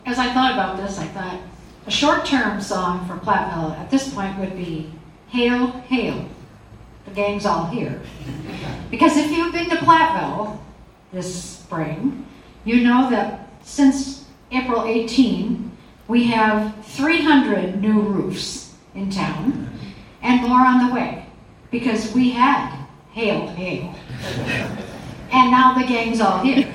On Friday, the Dubuque Area Chamber of Commerce hosted their semi-annual Tri-State Mayors Breakfast with mayors or leading officials of six local cities.
Platteville Council President Barb Daus chose a response to give the crowd a laugh.